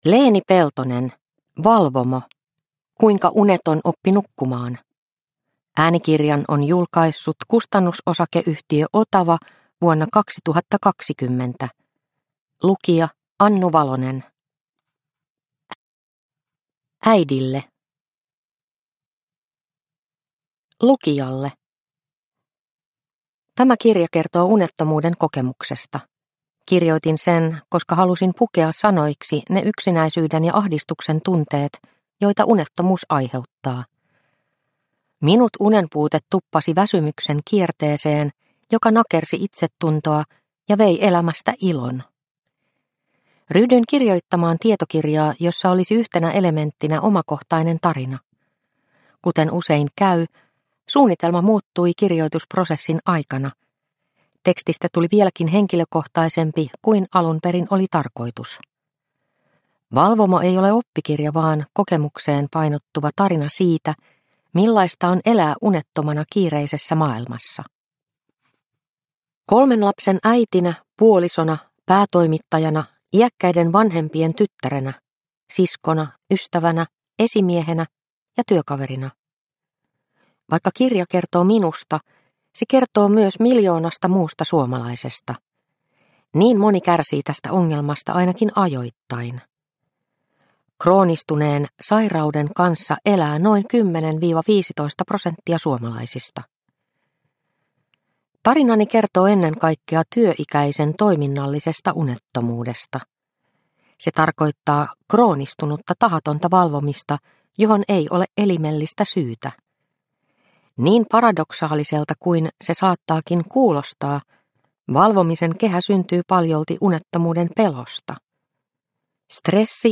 Valvomo – Ljudbok – Laddas ner